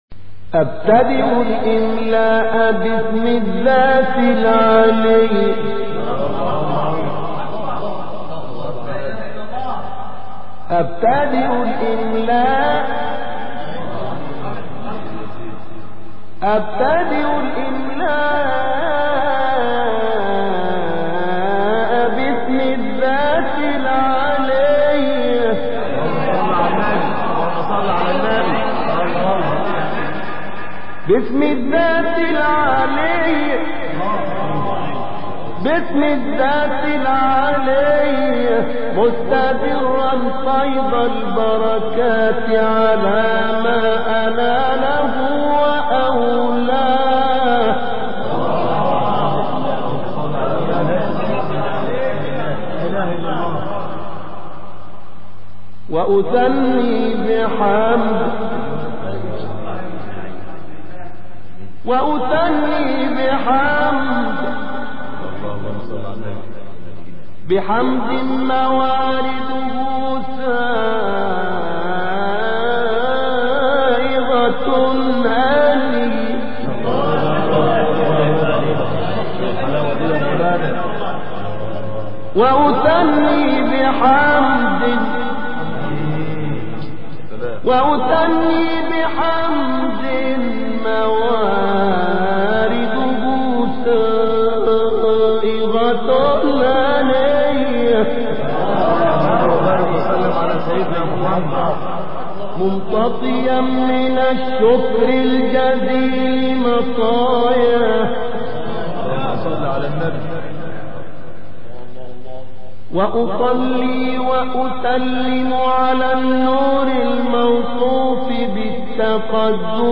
ابتهالی زیبا و روح‌نواز
ابتهال
قاری نابینا مصری